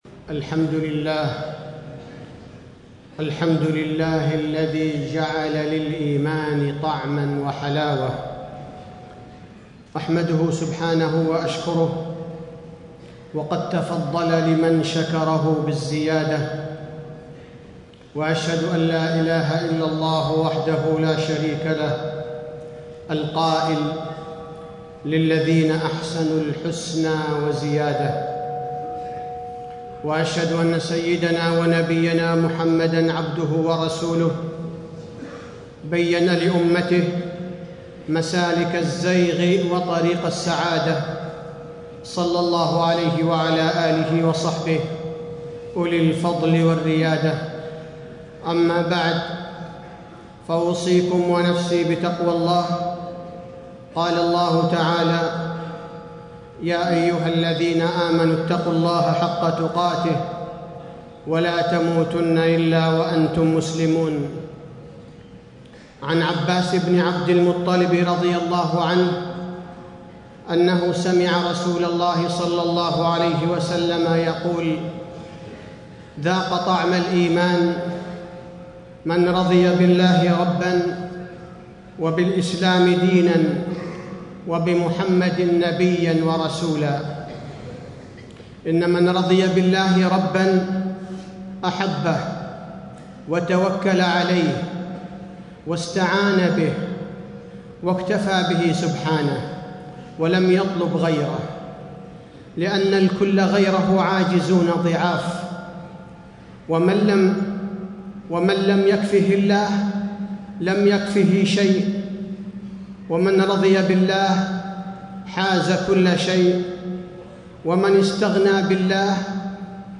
تاريخ النشر ١٦ ذو الحجة ١٤٣٥ هـ المكان: المسجد النبوي الشيخ: فضيلة الشيخ عبدالباري الثبيتي فضيلة الشيخ عبدالباري الثبيتي كيف تتذوق حلاوة الإيمان